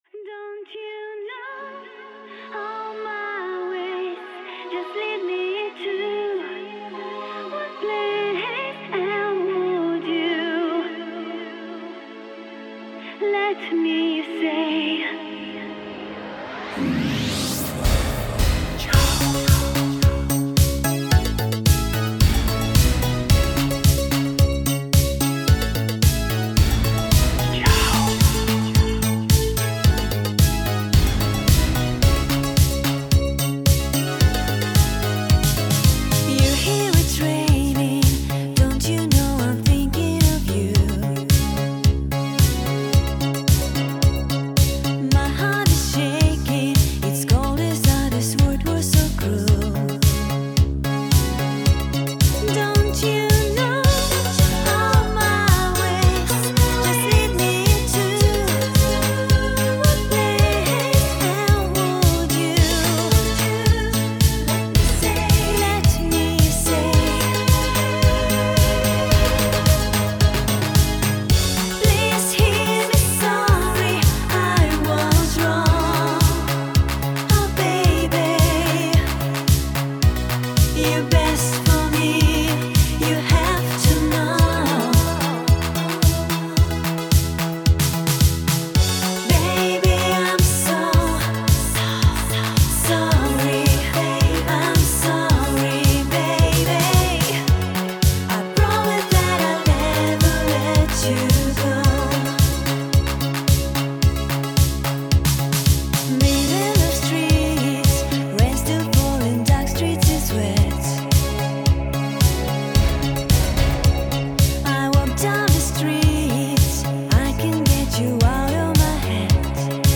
Жанр: Electronic
Стиль: Synth-pop, Disco